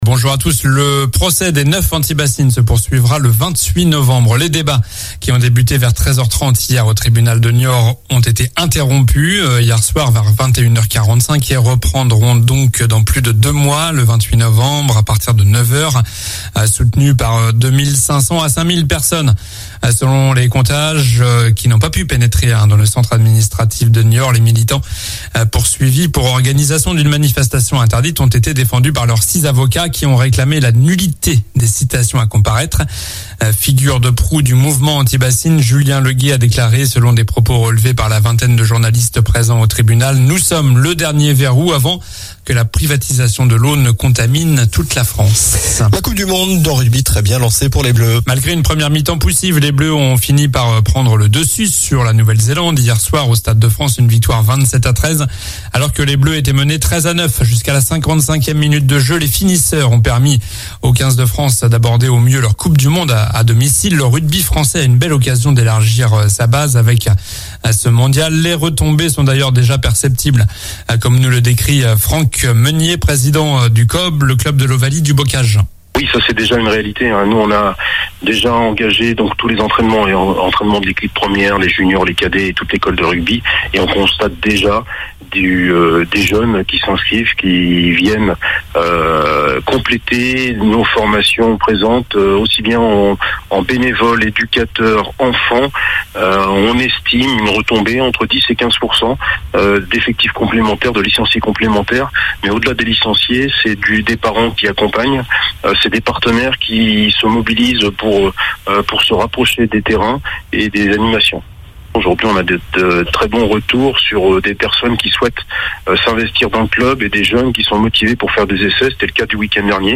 Journal du samedi 9 septembre (matin)